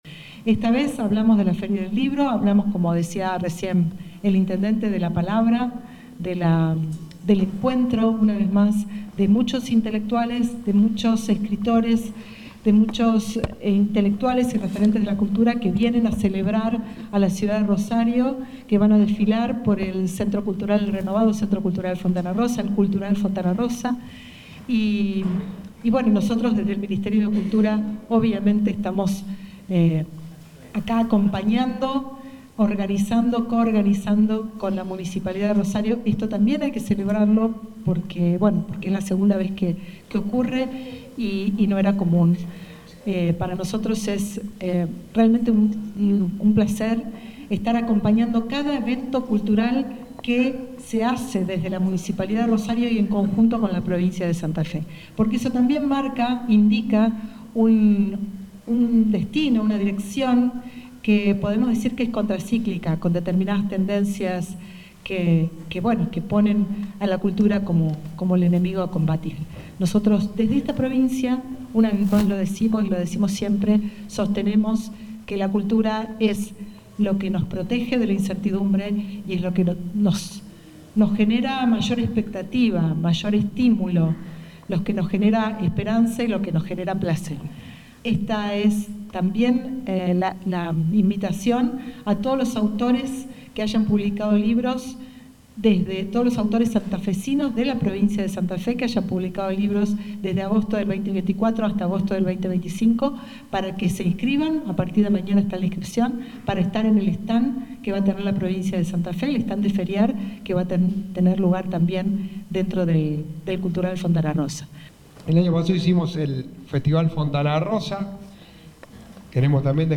Fragmentos de los discursos de Rueda y Javkin